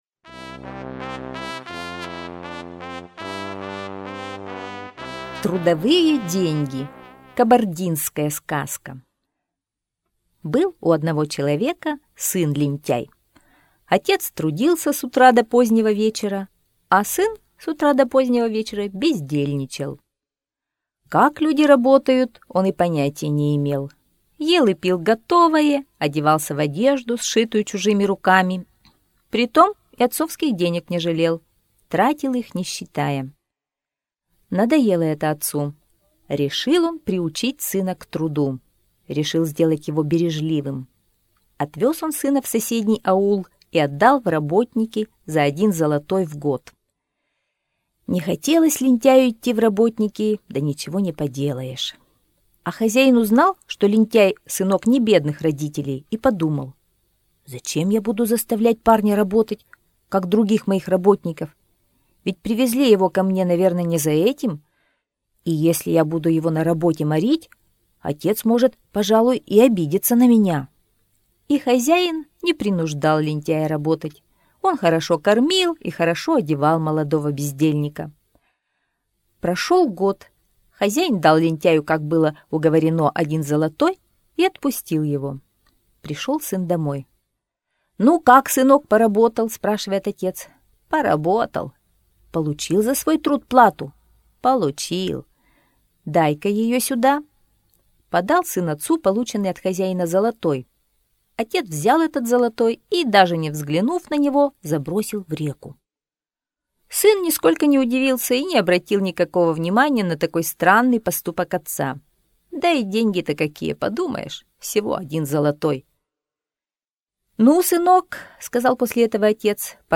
Трудовые деньги - кабардинская аудиосказка - слушать онлайн